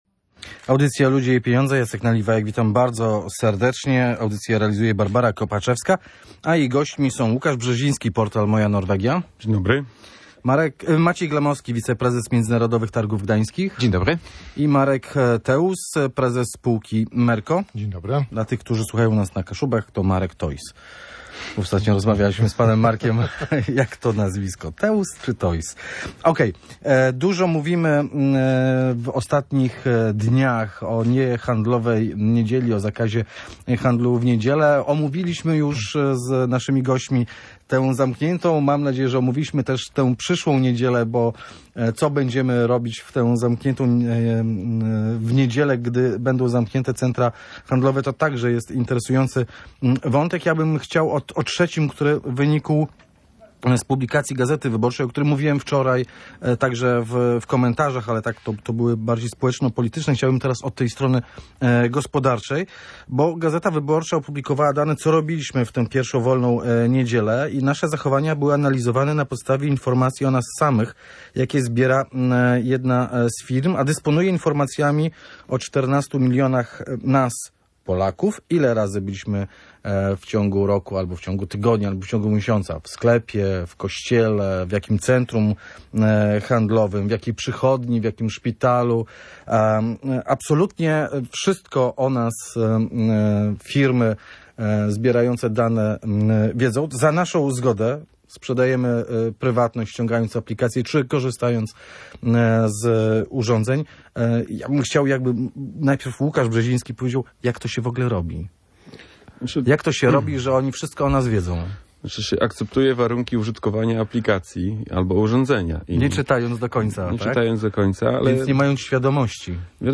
Goście Radia Gdańsk tłumaczyli, że często, akceptując warunki korzystania z aplikacji, nie czytamy tych warunków i nie zdajemy sobie sprawy z tego, na co się zgadzamy. Zazwyczaj zbierane dane są niezbędne do sprawnego działania usługi, ale często też są wykorzystywane później w różny sposób.